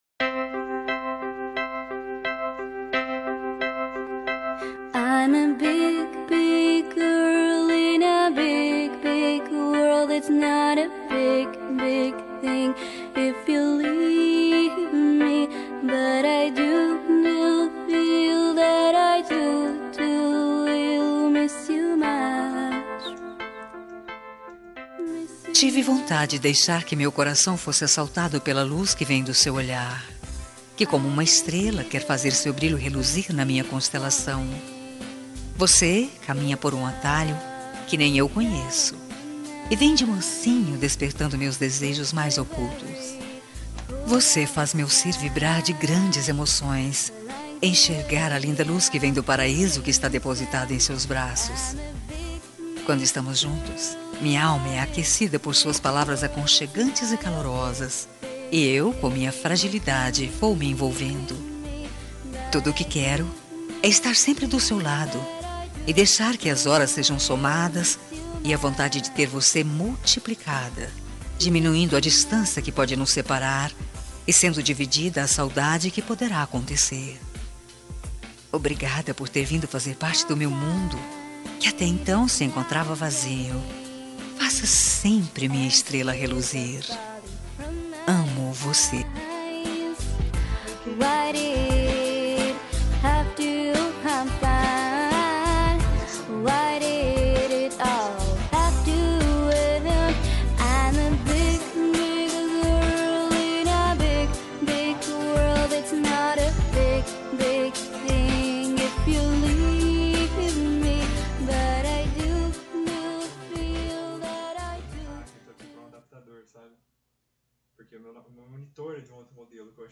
Telemensagem Romântica – Voz Feminina – Cód: 6732